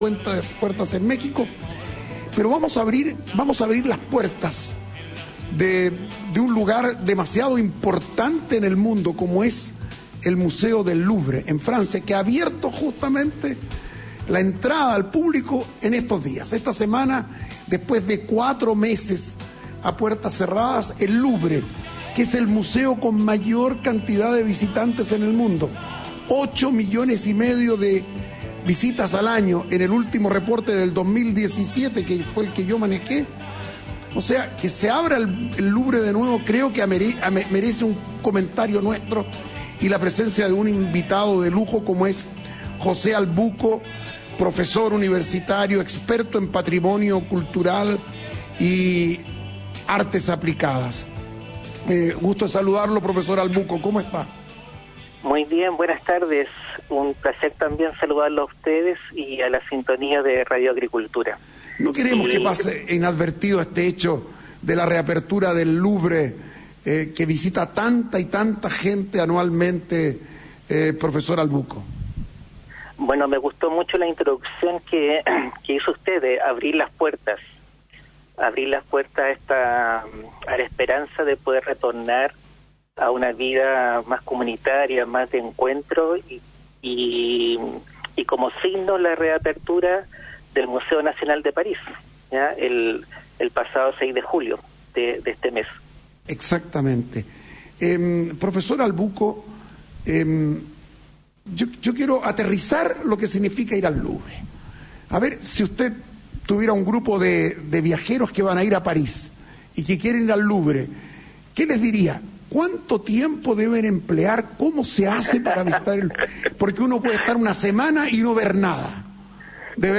En conversación con el programa El Rompecabezas